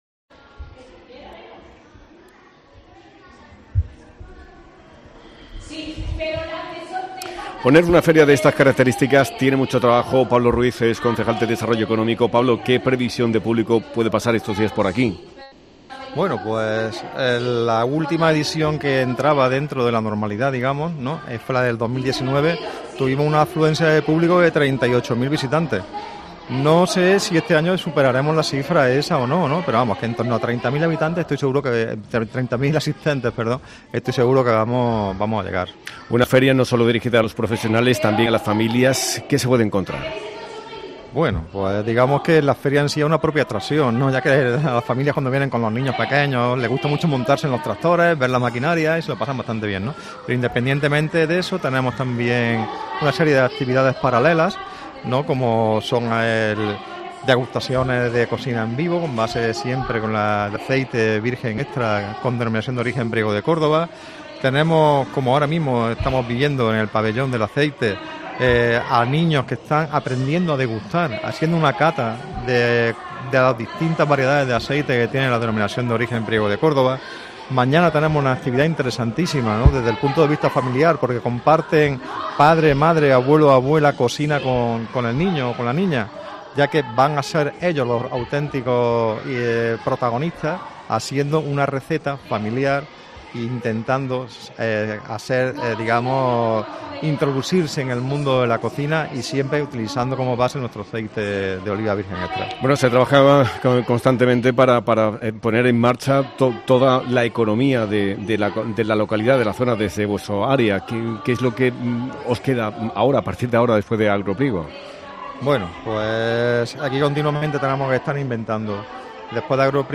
Pablo Ruíz, concejal de Desarrollo Económico del Ayuntamiento de Priego